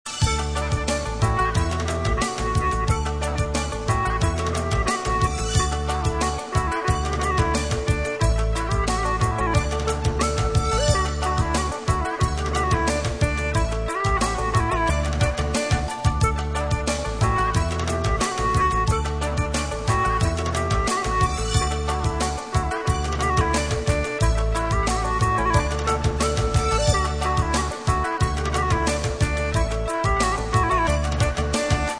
手机铃声